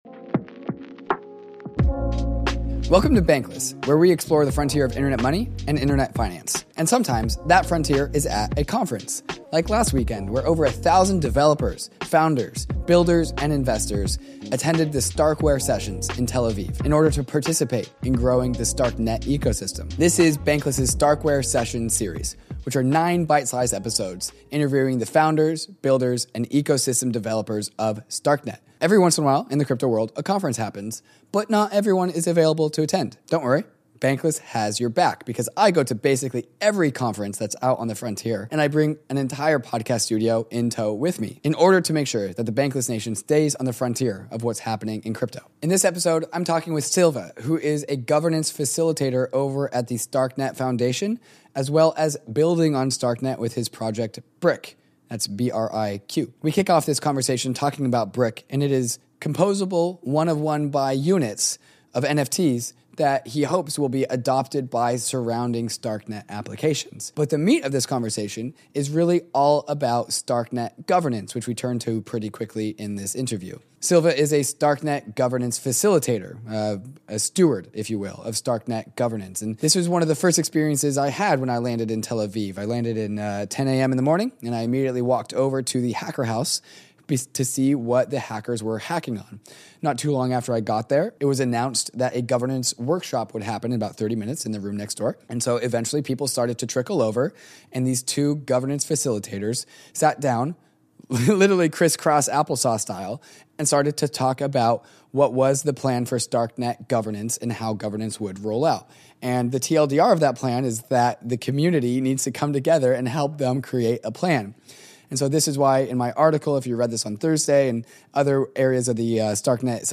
Sometimes, the frontier is at a crypto conference. We’re returning from our adventures in Tel Aviv with nine exclusive interviews with some of the key players in the StarkNet space.